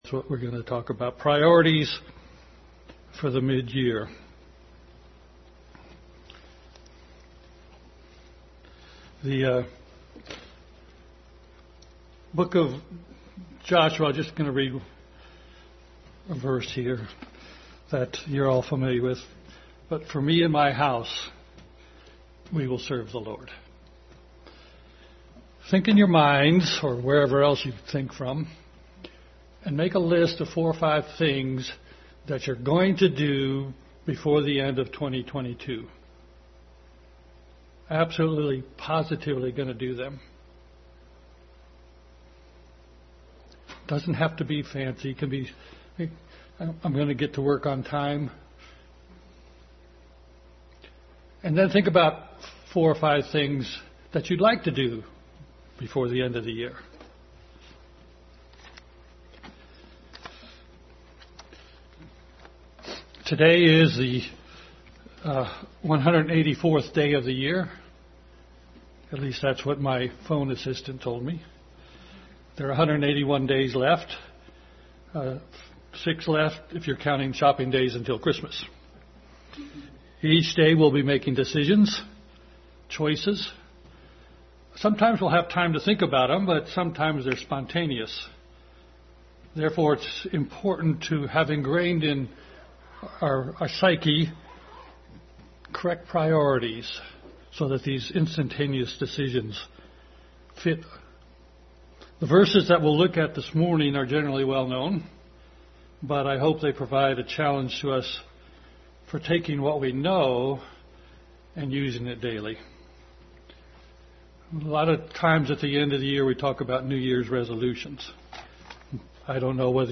Priorities for the Mid-Year Passage: Various Scriptures Service Type: Family Bible Hour Family Bible Hour Message.